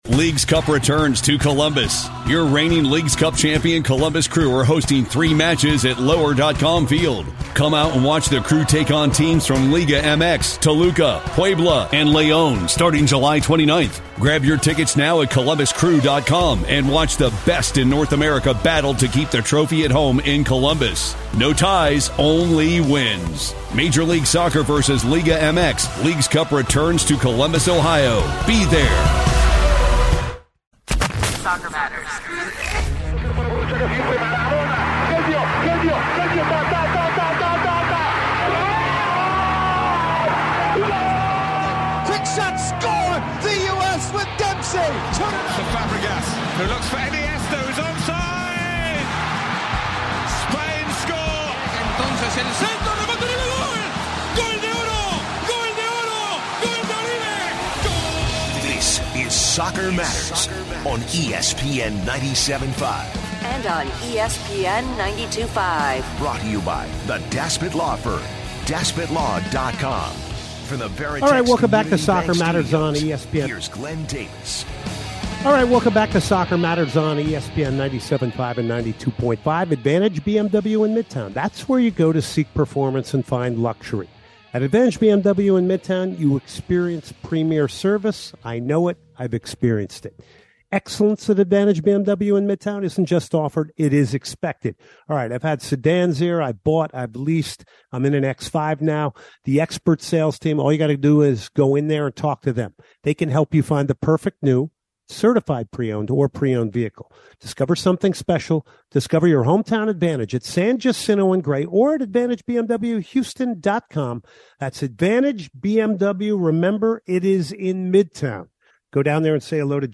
two interviews